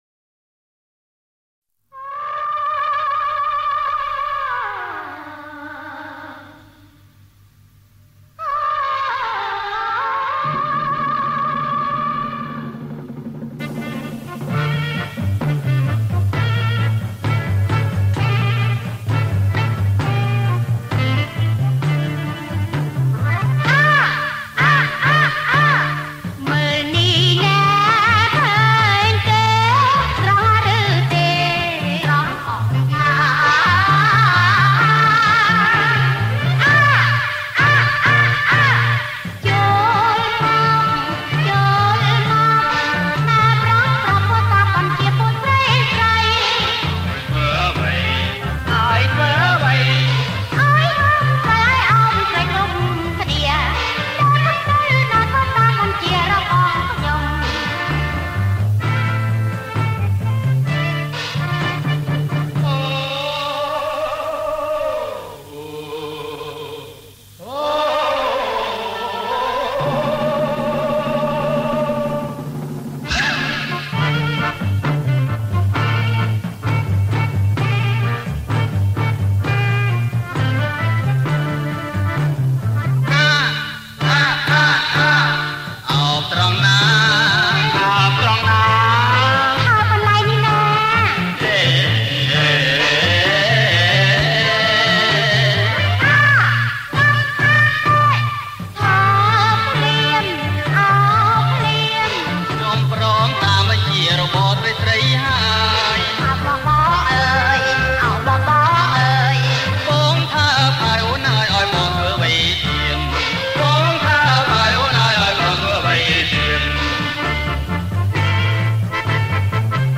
ប្រគំជាចង្វាក់ Bolero + Roam Lot